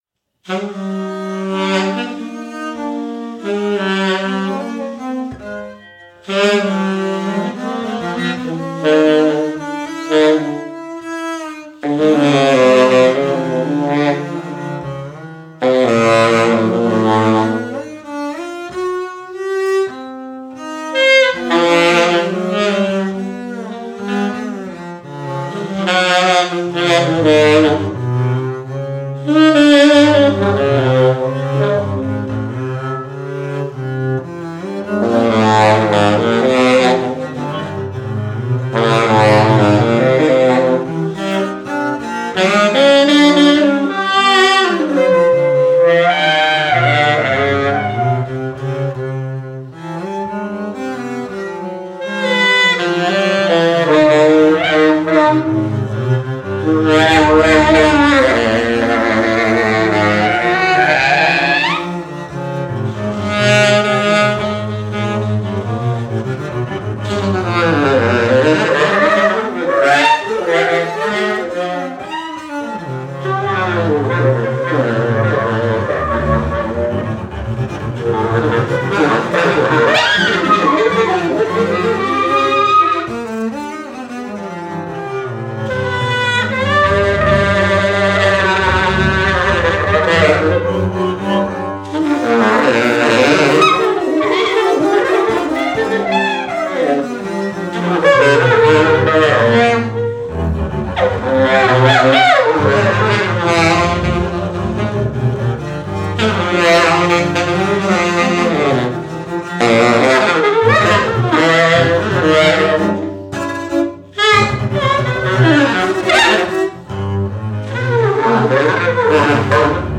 duo
tenor & alto sax
double bass